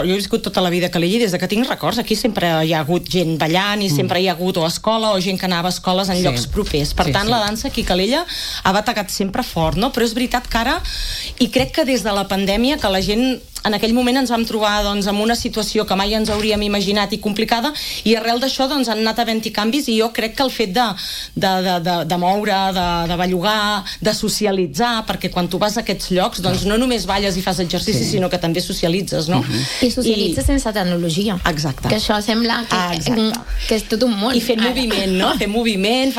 han passat pels micròfons del matinal de RCT